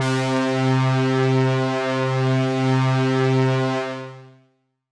Synth Pad C3.wav